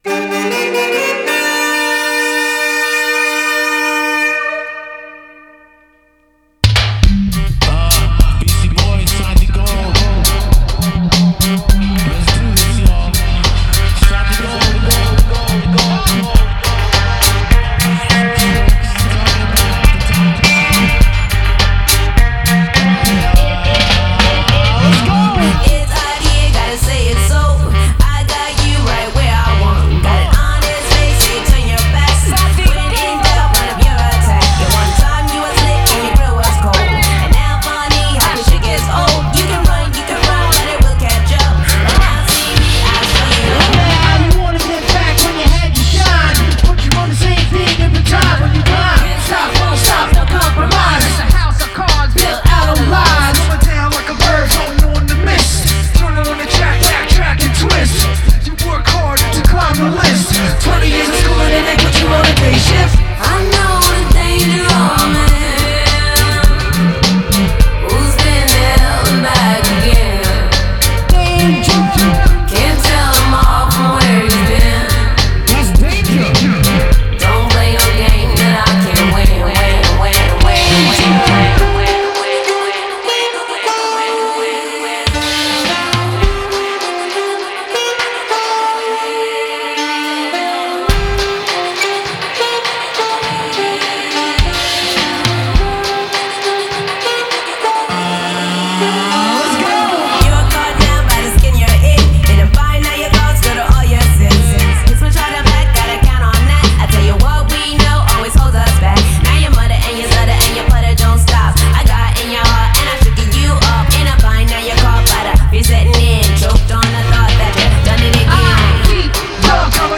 a fantastically dubby reggae track